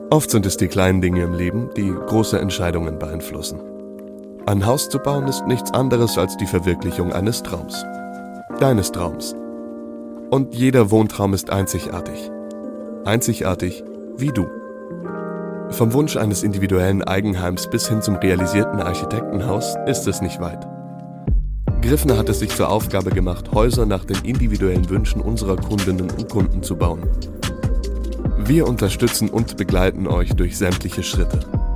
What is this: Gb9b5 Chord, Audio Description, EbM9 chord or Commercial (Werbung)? Commercial (Werbung)